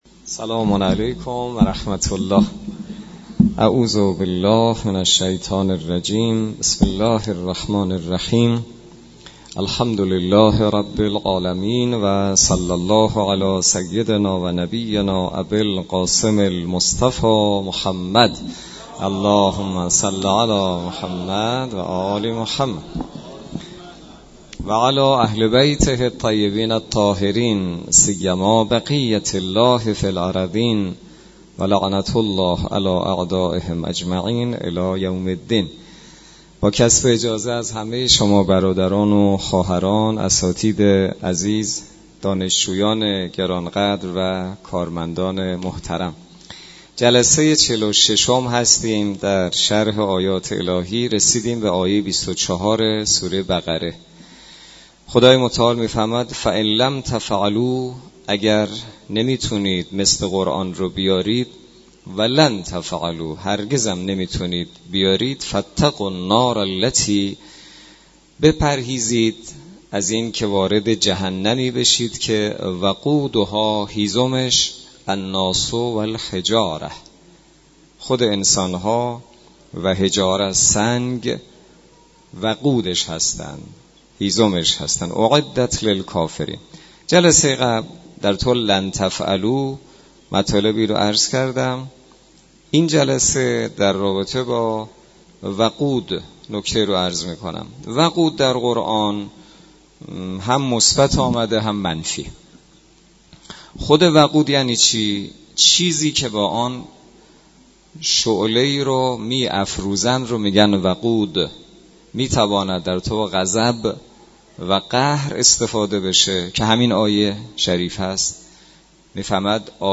برگزاری سی امین جلسه تفسیر سوره مبارکه بقره توسط امام جمعه کاشان در مسجد دانشگاه.
سی امین جلسه تفسیر سوره مبارکه بقره توسط حجت‌الاسلام والمسلمین حسینی نماینده محترم ولی فقیه و امام جمعه کاشان در مسجد دانشگاه کاشان برگزار گردید.